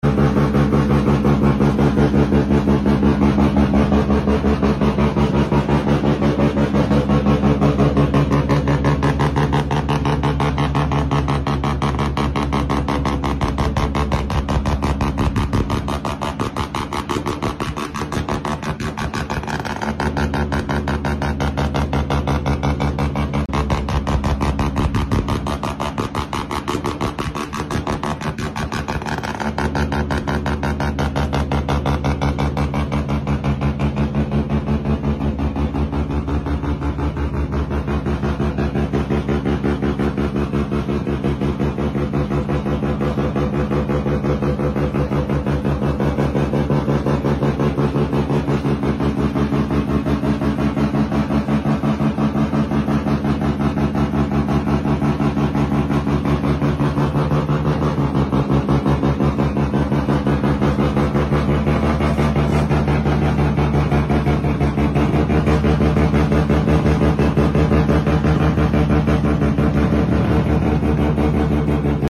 Mazda RX 7 Spirit Sound Effects Free Download